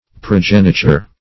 Progeniture \Pro*gen"i*ture\, n.